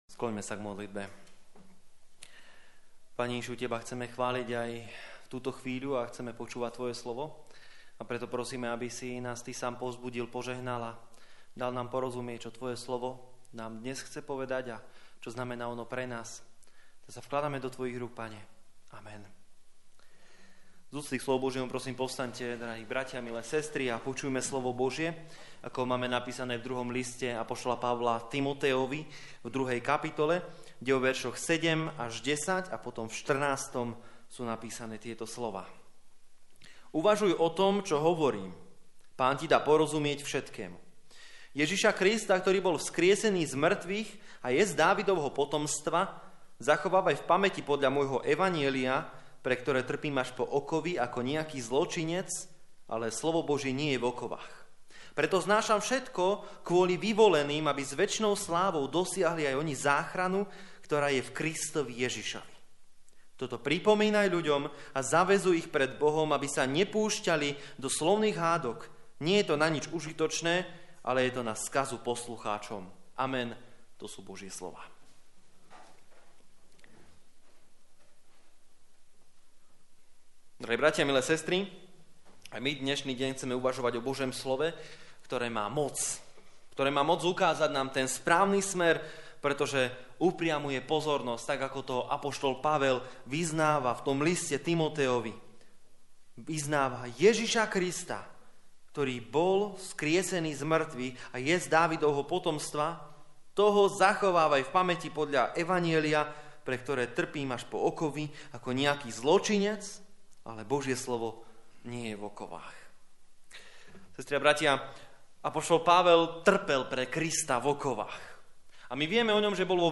10. 14 Service Type: Služby Božie 4. nedeľa po Veľkej noci « Buď živá ratolesť a zostaň v Kristovi!